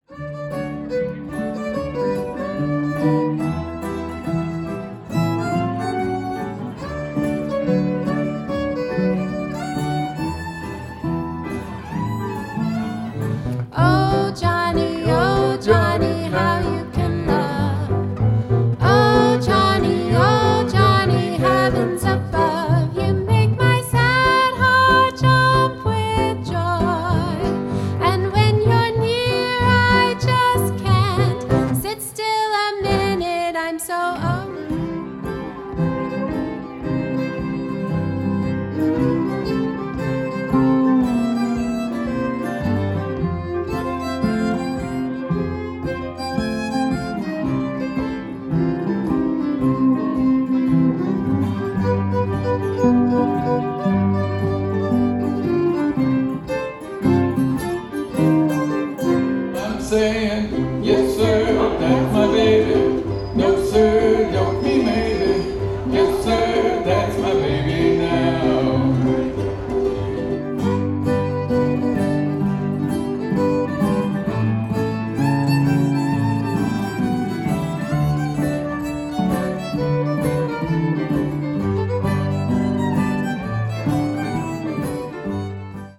Vintage Jazz
vintage jazz band